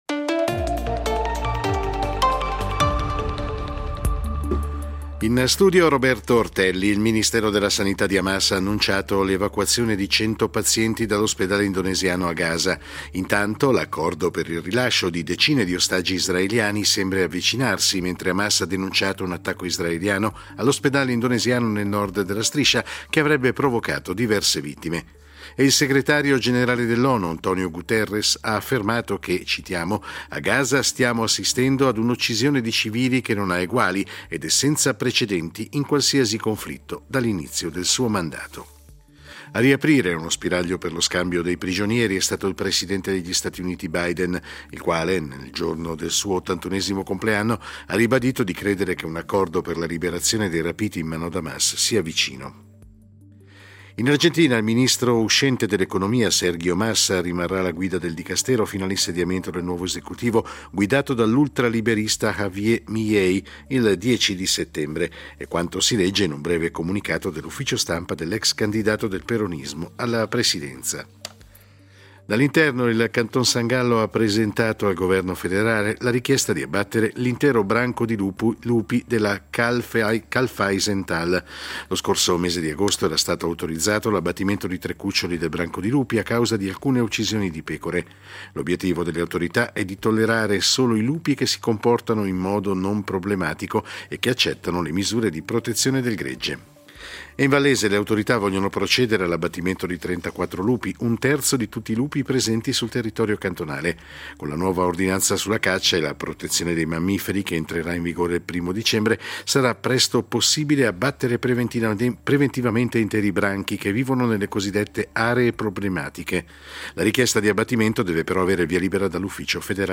Notiziario delle 22:00 del 20.11.2023